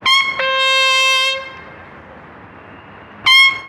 Toque de corneta 2
aerófono
corneta
llamada
metal
militar